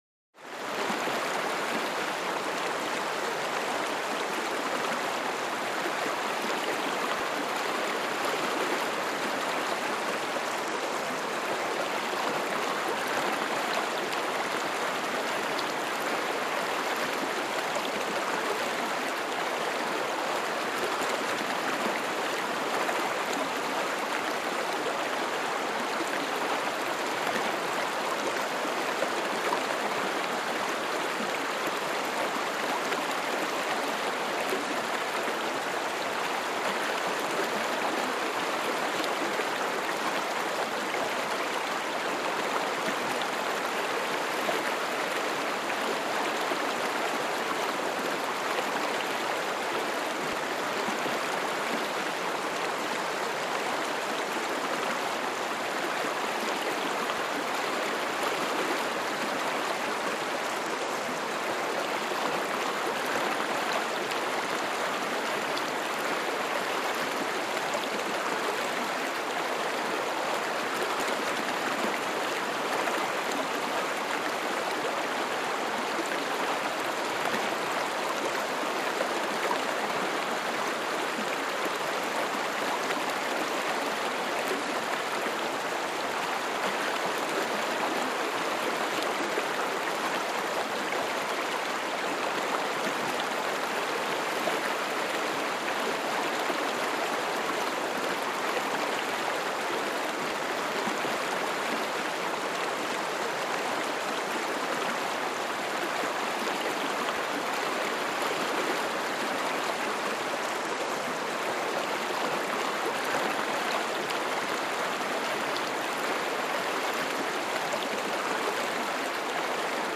Creek / Stream - Medium Distance